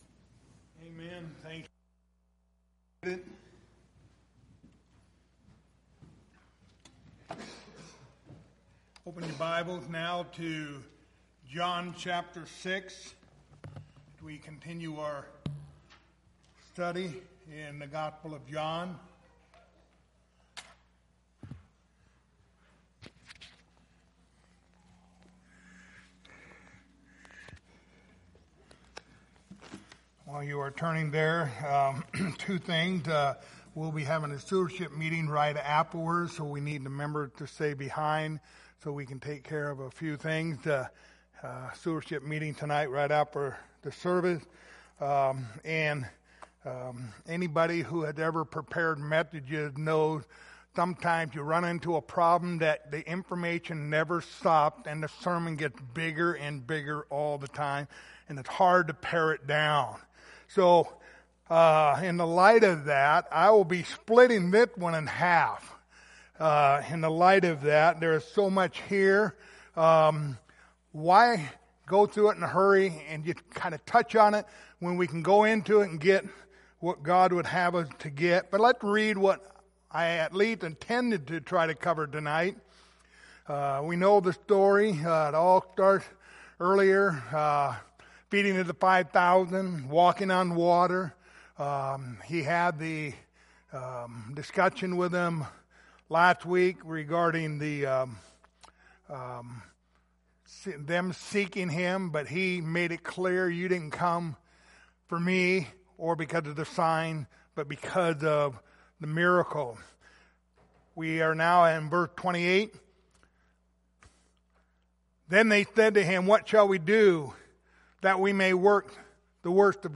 Passage: John 6:28-34 Service Type: Wednesday Evening